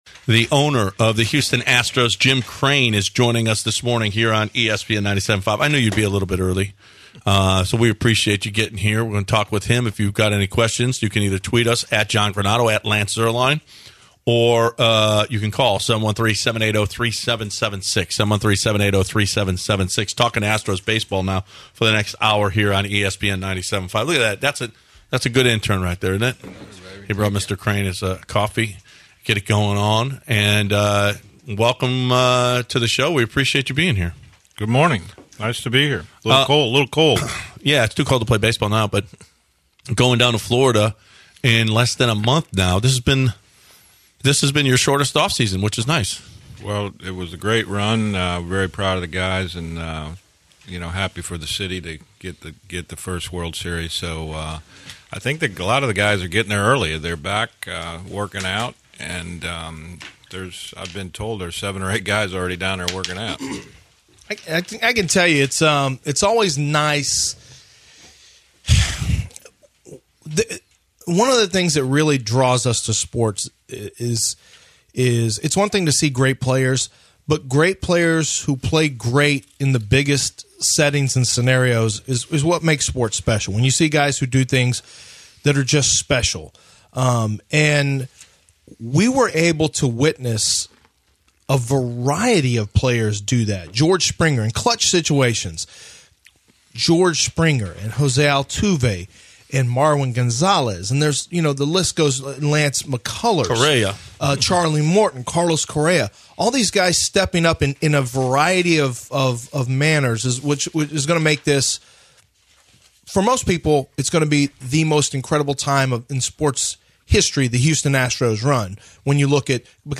Full Jim Crane Interview